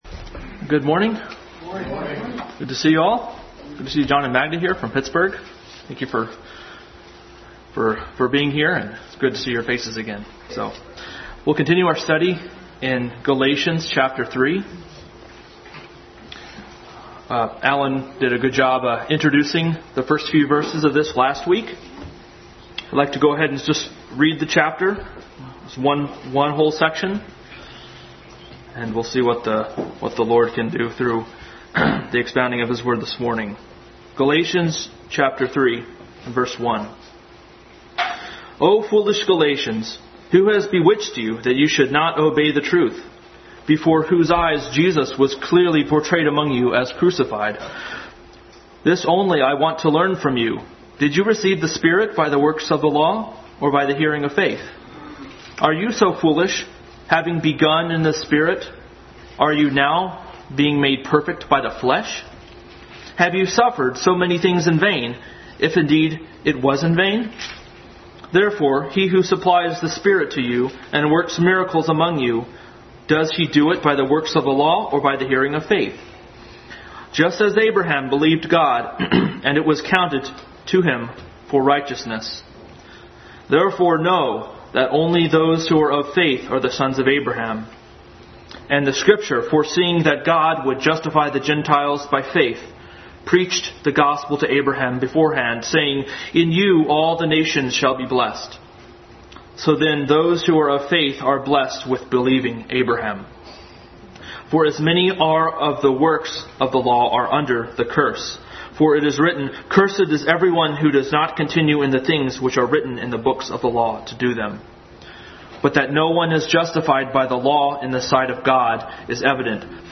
Adult Sunday School Class continued study in Galatians.